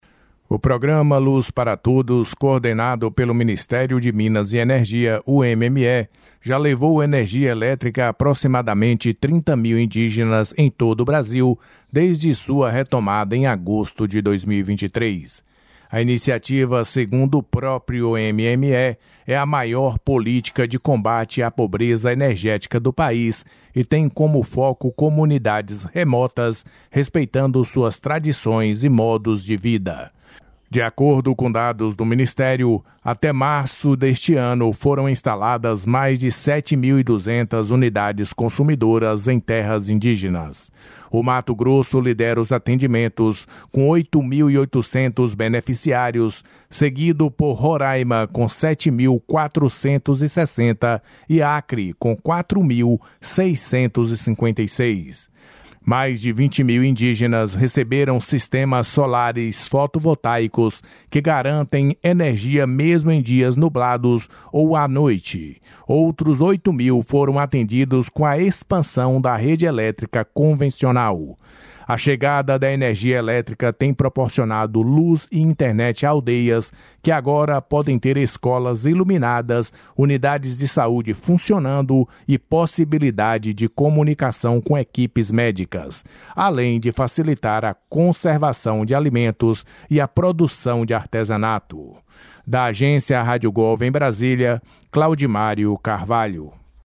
31/08/21 - PRONUNCIAMENTO DO MINISTRO DE MINAS E ENERGIA, BENTO ALBUQUERQUE